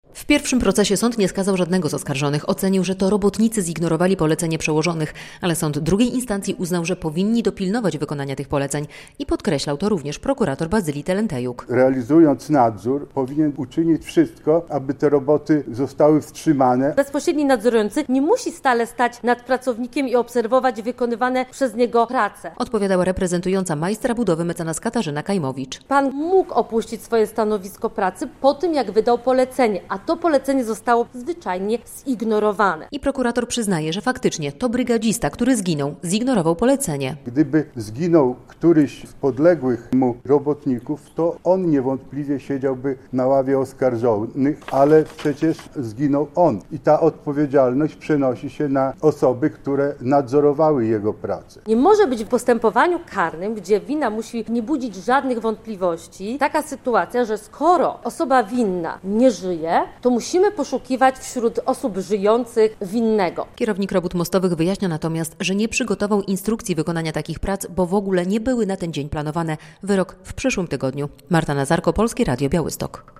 Zakończył się ponowny proces w sprawie śmiertelnego wypadku przy przebudowie ul. Klepackiej w Białymstoku - relacja